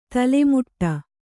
♪ tale muṭṭa